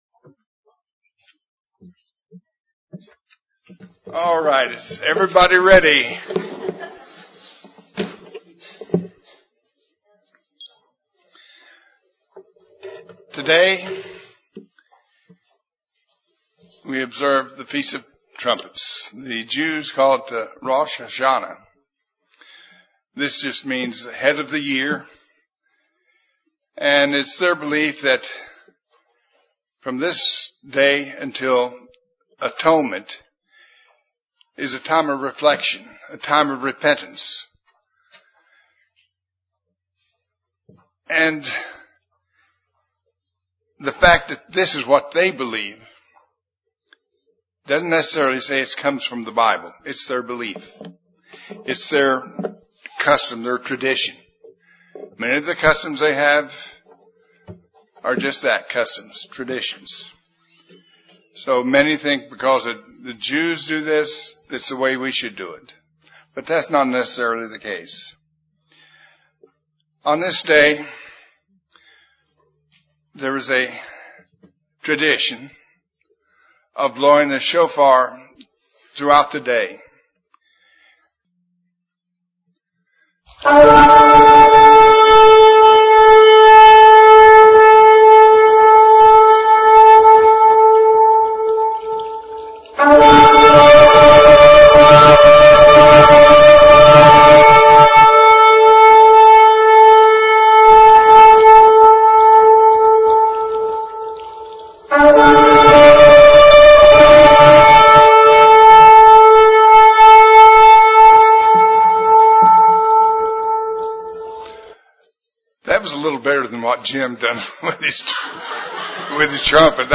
This sermon examines the events surround the Feast of Trumpets and what we can expect to see and may experience when the trumpets begin to sound.